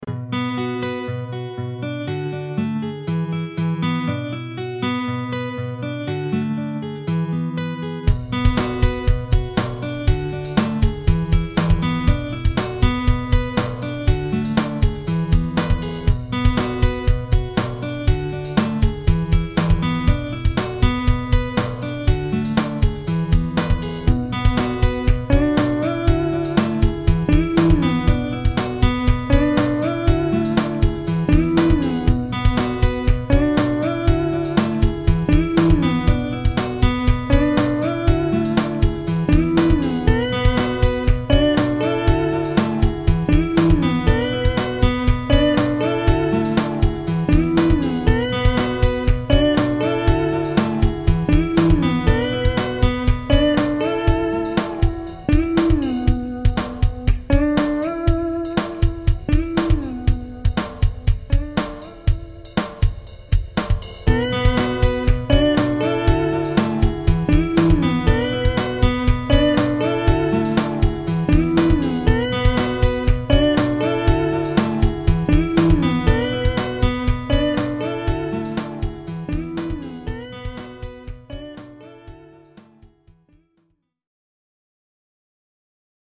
The Recalibration Code Book Guided Session 1 –
This is the first guided session from chapter 1.